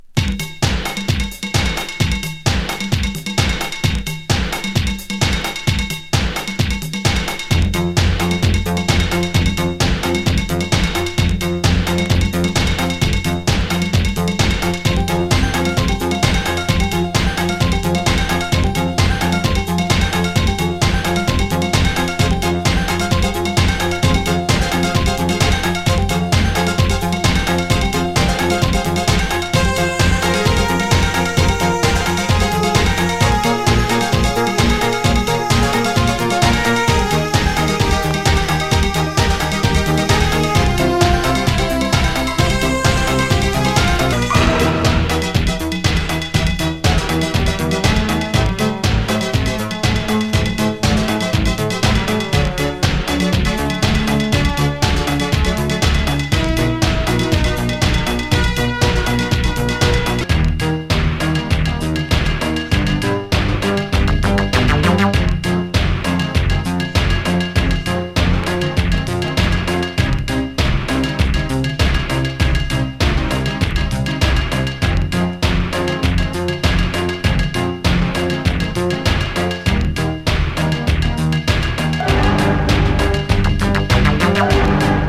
インストver-8でBPM120怪奇ディスコ化、スクリューでコズミック化も◎